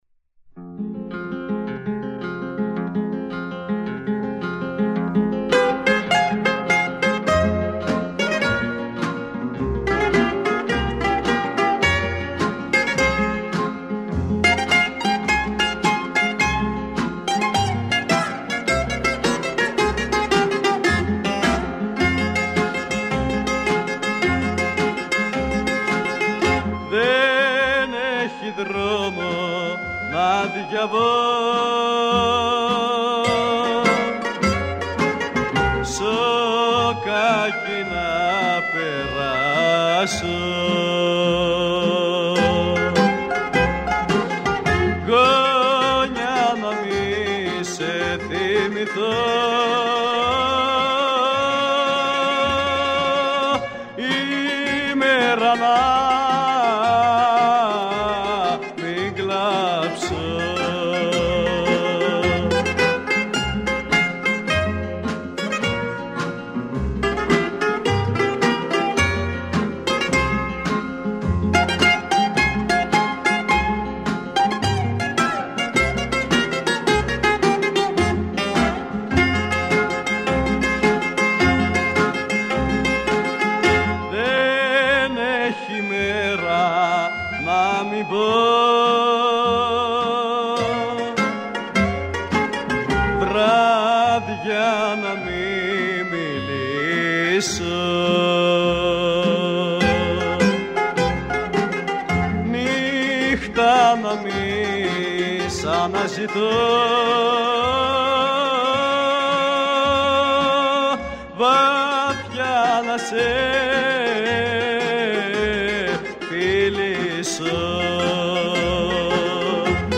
Popular Greek bouzouki music.
Popular Songs and Instrumental Music